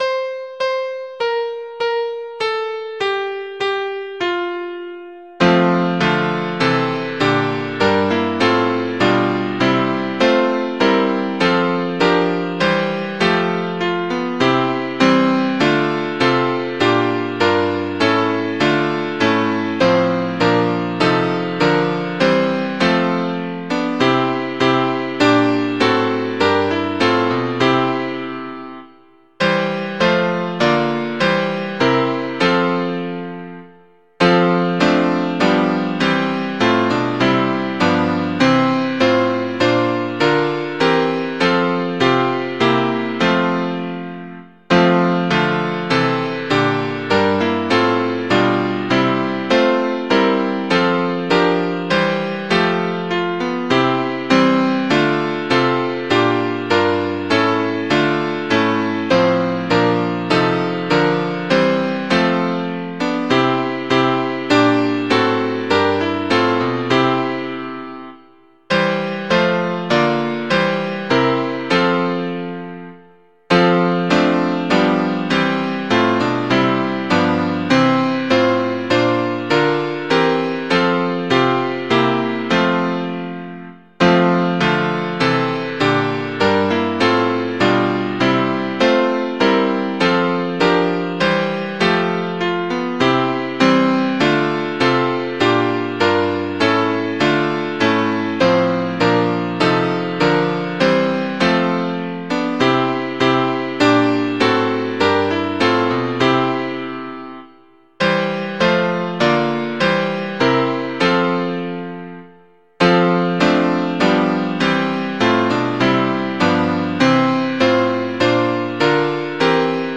Music: 'Der Lieben Sonne Lich Und Pracht' or 'Ristad' from Freylinghausen's Geistreiches Gesangbuch, Halle, 1704.
Mp3 Audio of Tune Abc source